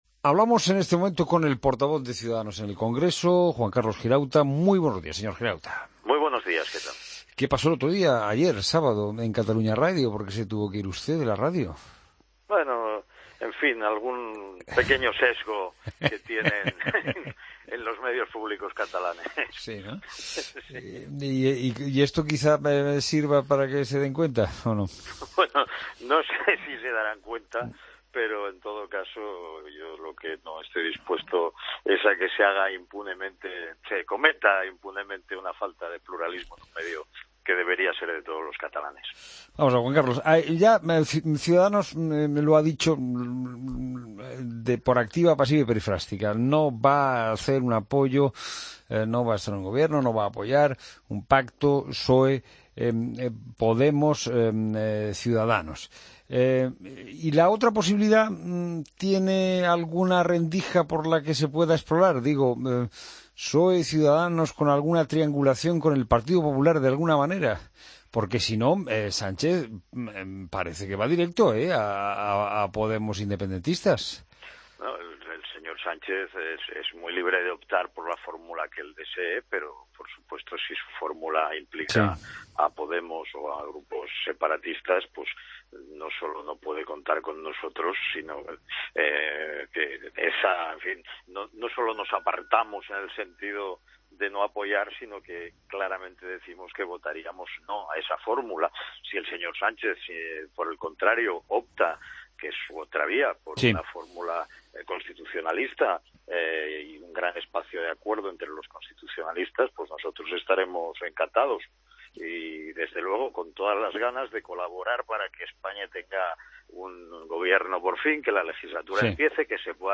Escucha la entrevista a Juan Carlos Girauta, portavoz de Ciudadanos en el Congreso, en La Mañana del Fin de Semana.